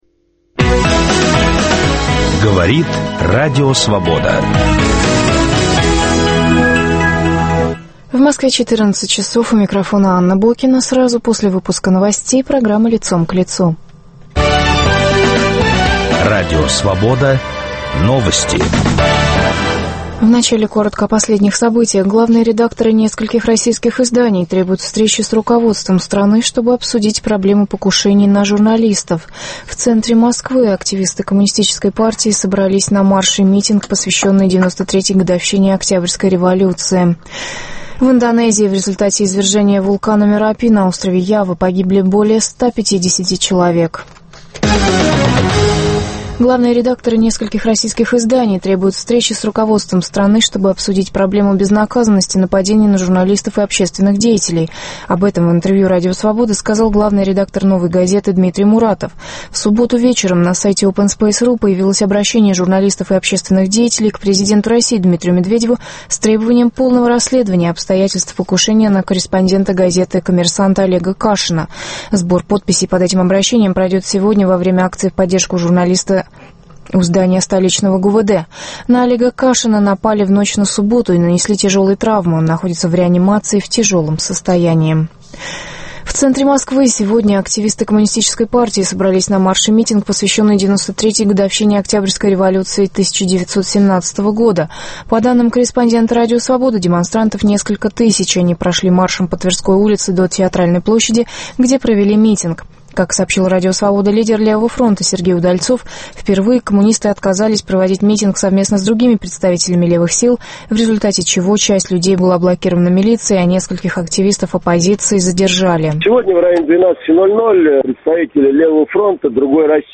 Лидер в той или иной сфере общественной жизни - человек известный и информированный - под перекрестным огнем вопросов трех журналистов: российского, иностранного и ведущего "Свободы".